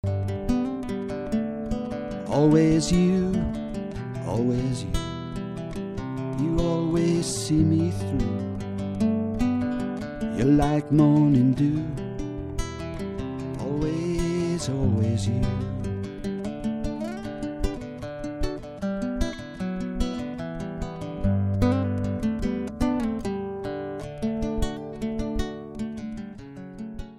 Ashington Folk Club - Spotlight 21 July 2005